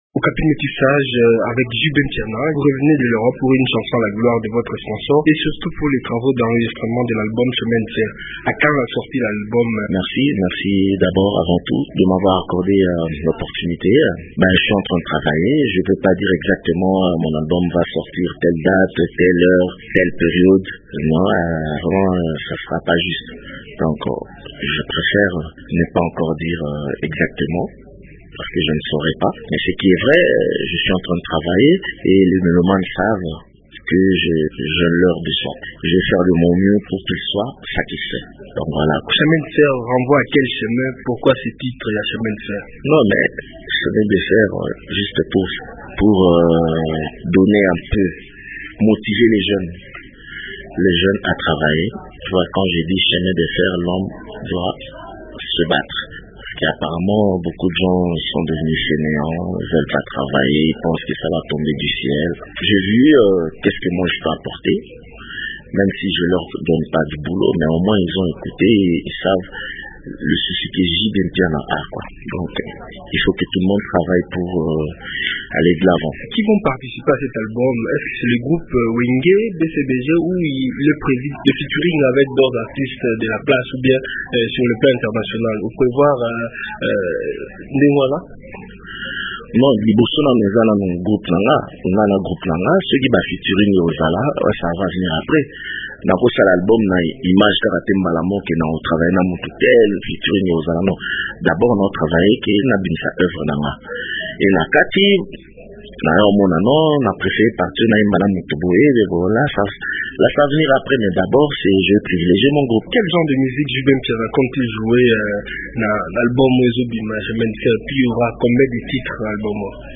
Patron du groupe Wenge BCBG, JB Mpiana fait le bilan de son dernier album, « Quel est ton problème » et parle des préparatifs de son prochain album, « Chemin de fer » dans cet entretien